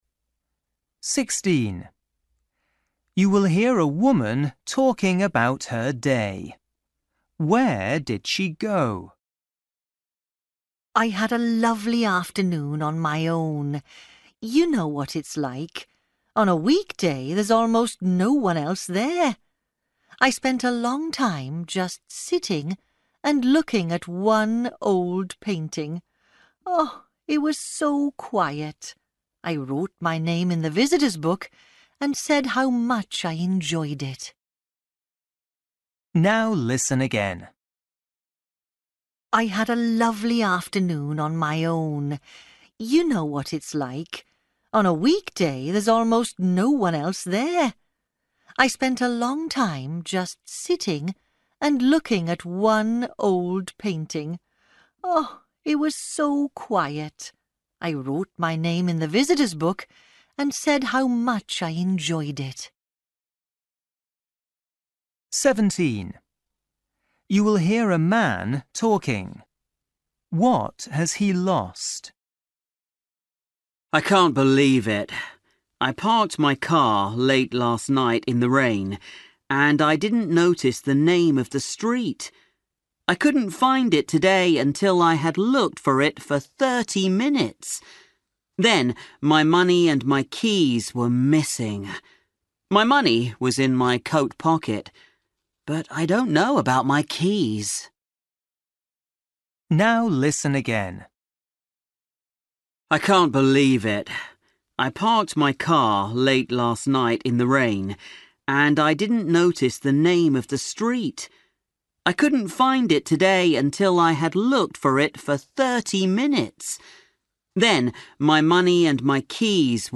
Listening: everyday short conversations
16   You will hear a woman talking about her day. Where did she go?
18   You will hear two friends talking. What will they do this afternoon?
20   You will hear a man talking in a shop. What is he looking at?